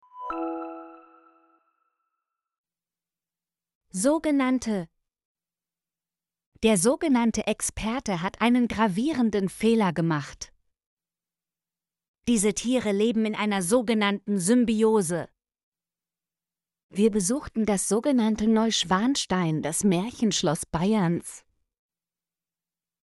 sogenannte - Example Sentences & Pronunciation, German Frequency List